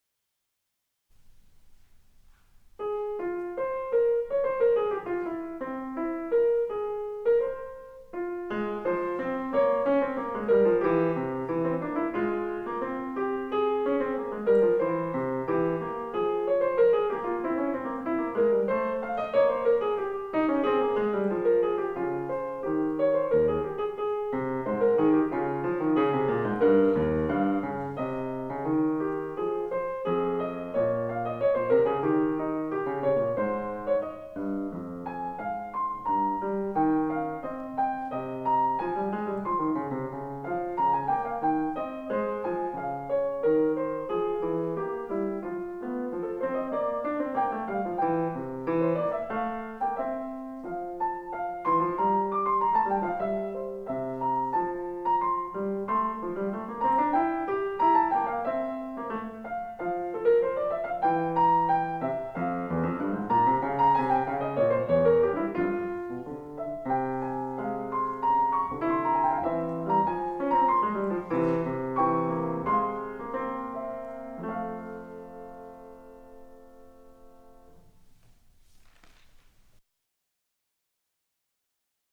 自作自演
2002.3.3 イズミティ２１ 小ホール
自分の作品なのにミスタッチ多いです・・・。